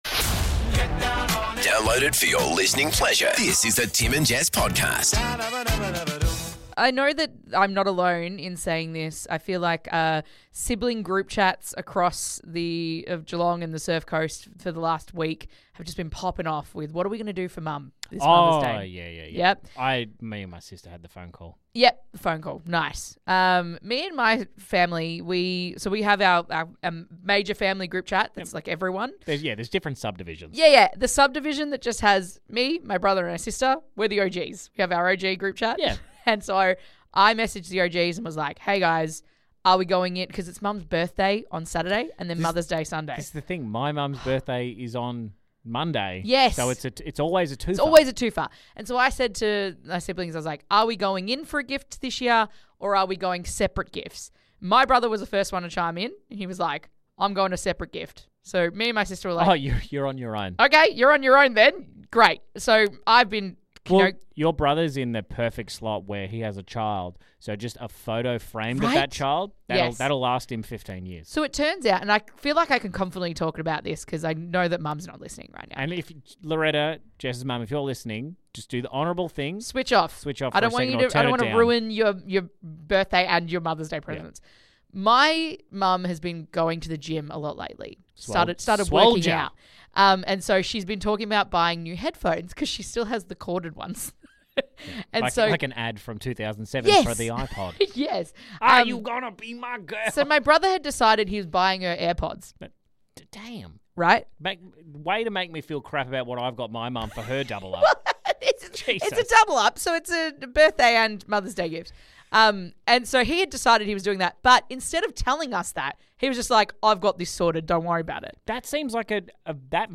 - Family group chat chaos - What the f&#@ happened to the Milky Bar Kid?!? - Texts that you hate - Guest: Bob Hardy from Franz Ferdinand